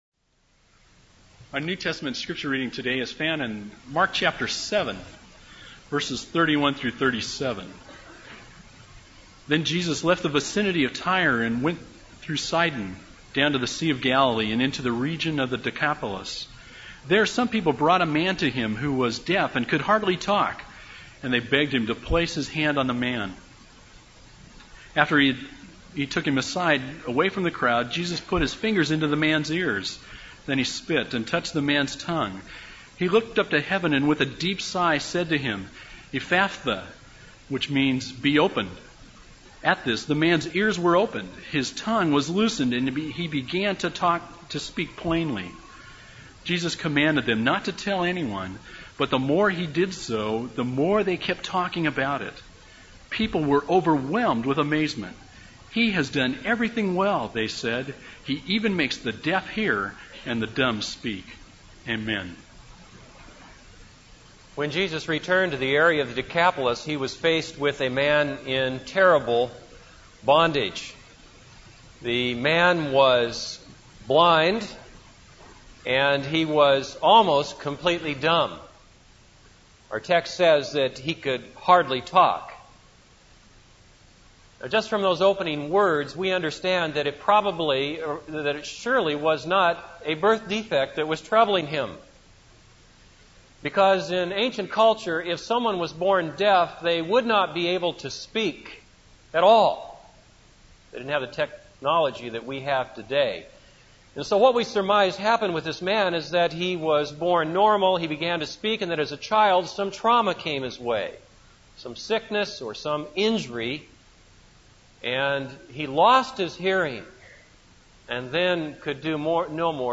This is a sermon on Mark 7:31-37.